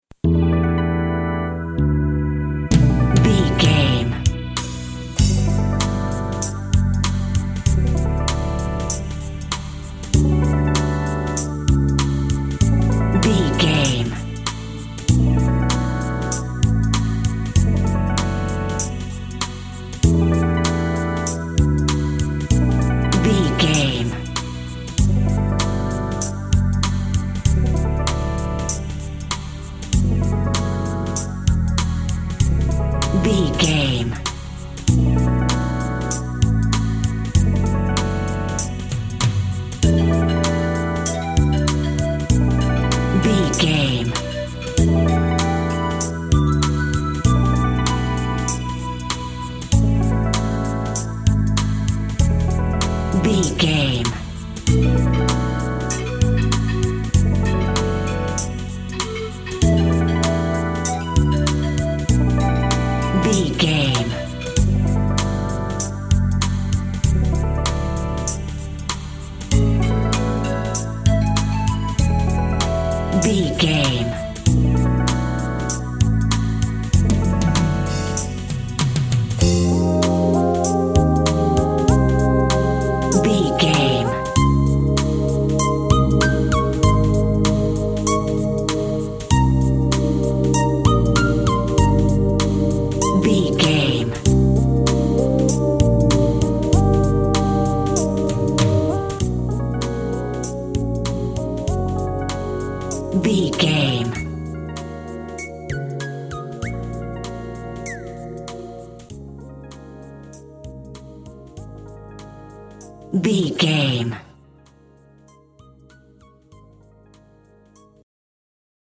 Aeolian/Minor
melancholy
futuristic
hypnotic
dreamy
groovy
drums
synthesiser
electric piano
electric guitar
electronic
80s music
synth bass
synth lead